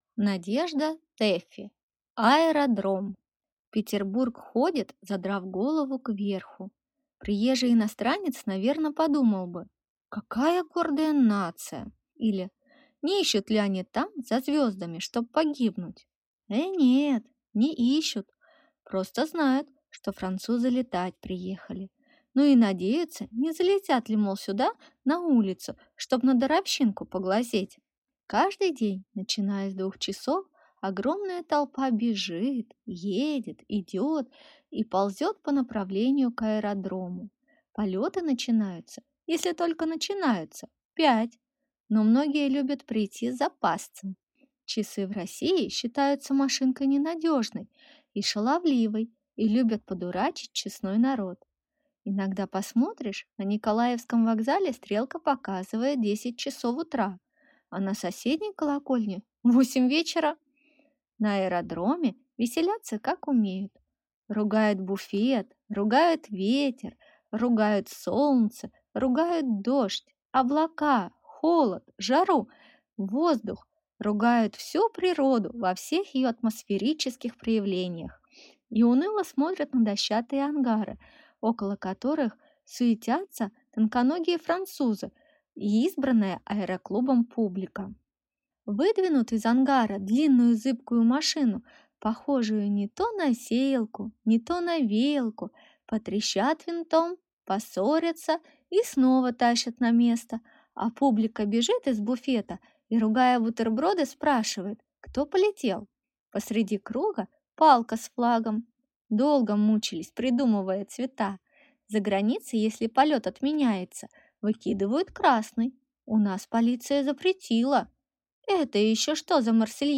Аудиокнига Аэродром | Библиотека аудиокниг
Прослушать и бесплатно скачать фрагмент аудиокниги